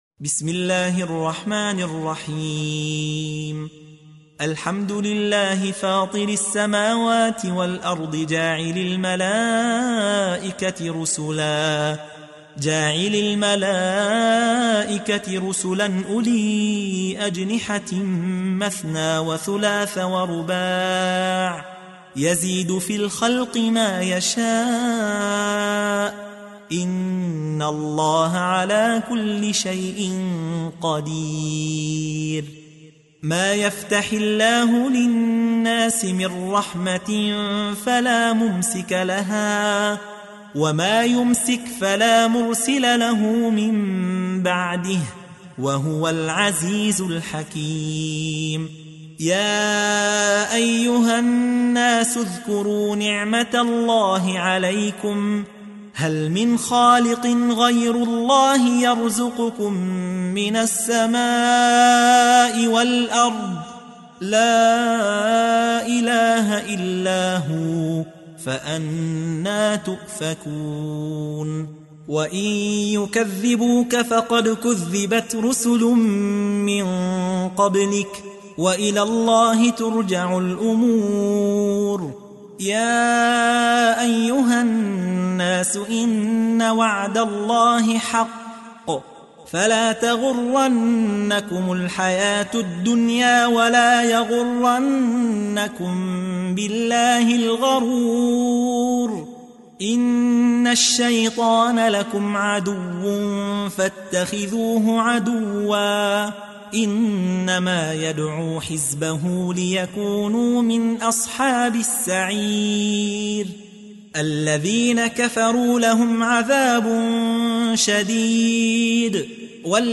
تحميل : 35. سورة فاطر / القارئ يحيى حوا / القرآن الكريم / موقع يا حسين